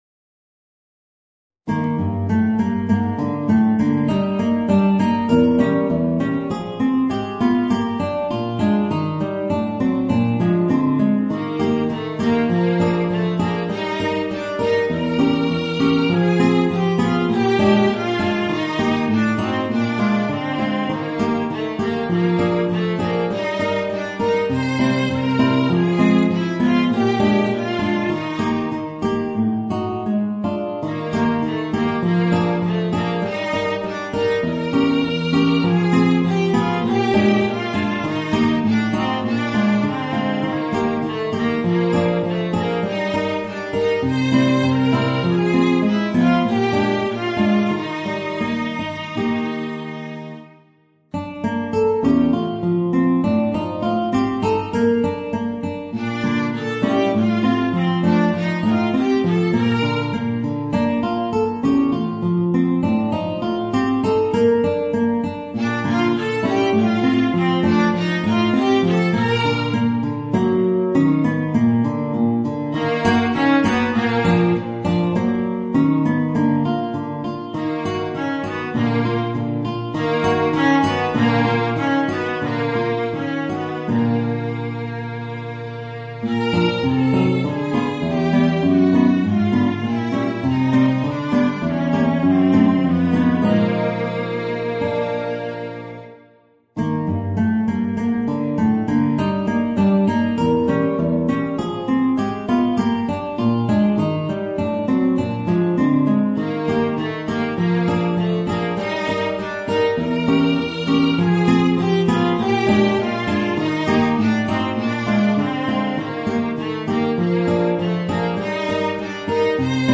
Voicing: Viola and Guitar